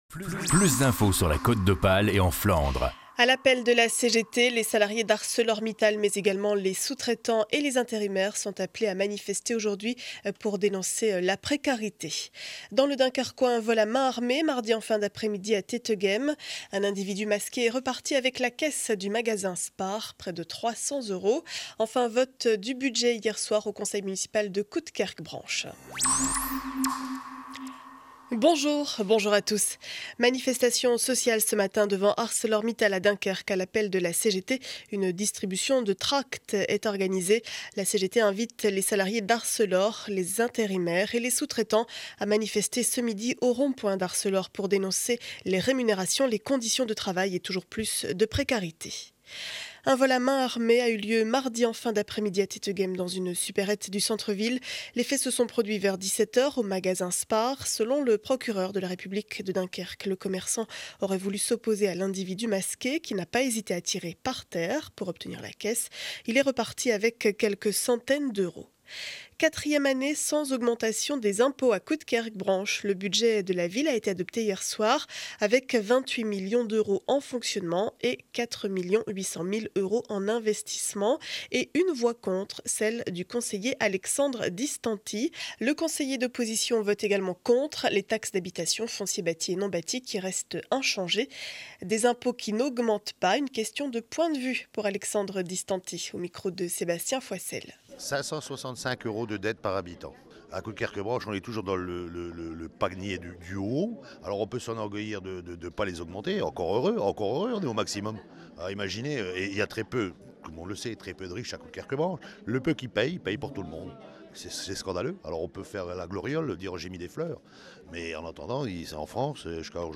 Journal du jeudi 12 avril 2012 7 heures 30 édition du Dunkerquois.